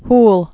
(hl)